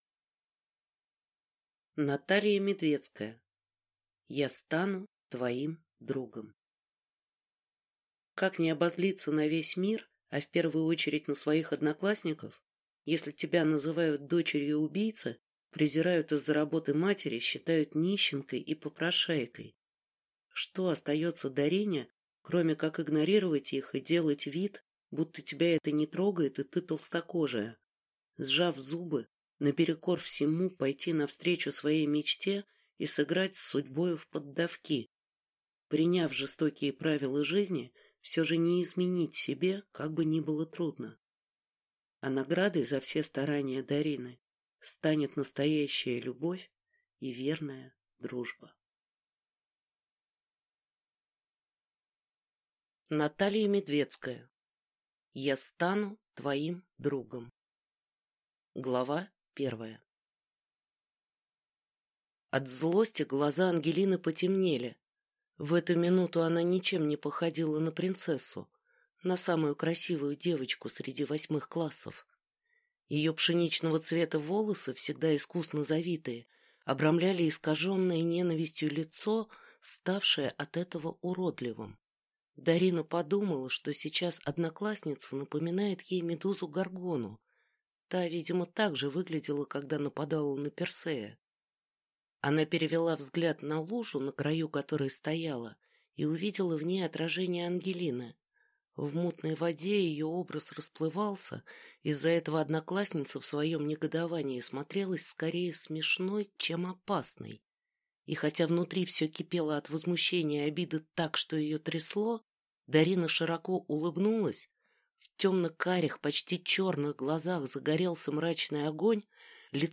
Аудиокнига Я стану твоим другом | Библиотека аудиокниг